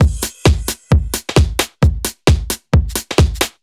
Index of /musicradar/uk-garage-samples/132bpm Lines n Loops/Beats
GA_BeatD132-01.wav